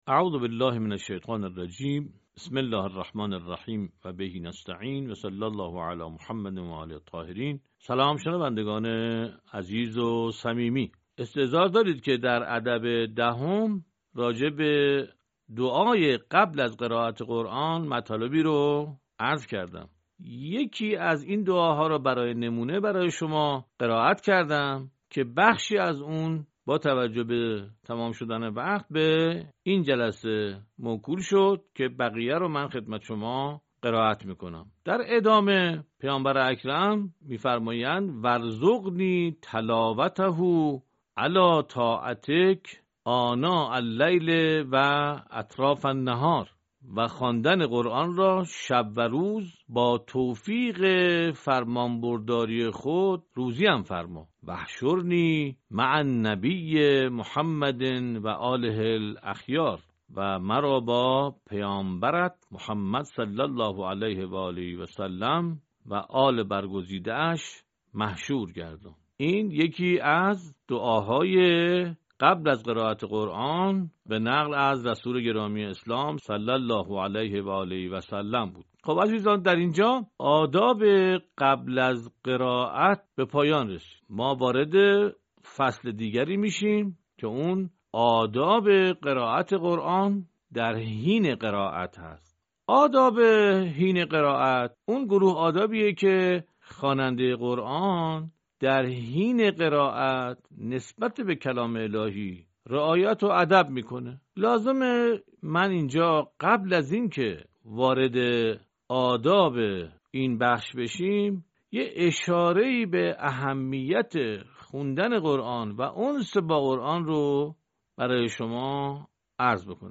صوت | ادعیه تلاوت قرآن کریم